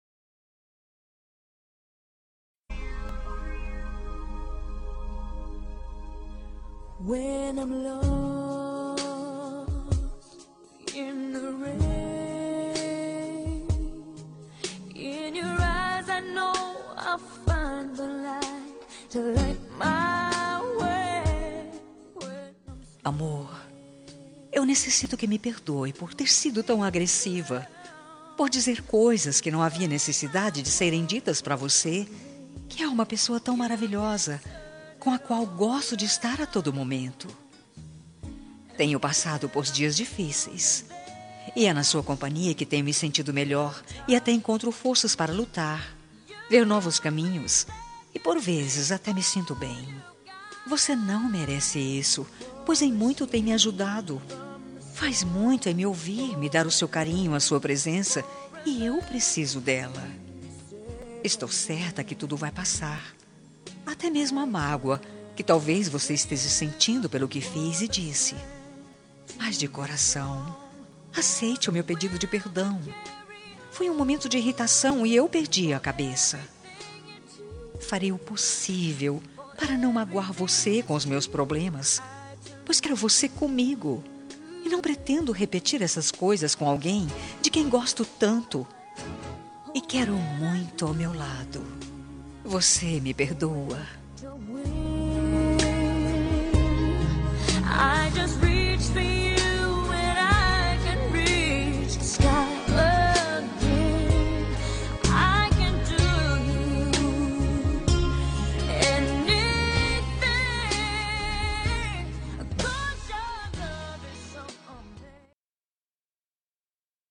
Telemensagem de Desculpas – Voz Feminina – Cód: 201812